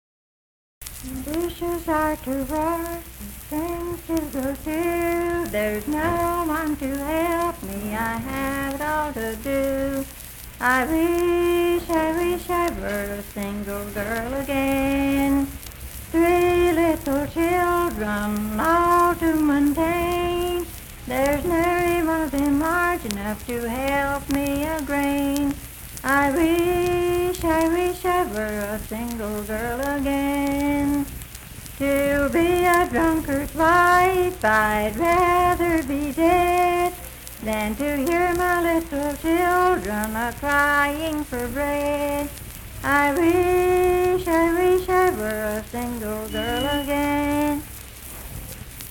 Unaccompanied vocal music
Verse-refrain 3(3).
Voice (sung)
Roane County (W. Va.), Spencer (W. Va.)